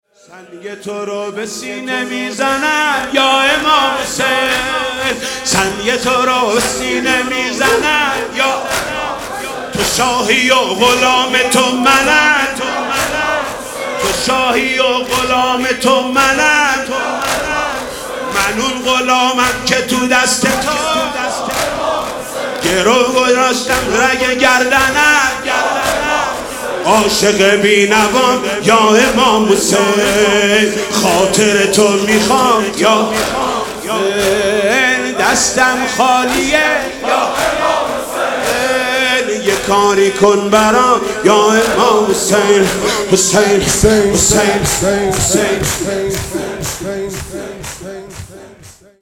مناسبت : شهادت حضرت فاطمه زهرا سلام‌الله‌علیها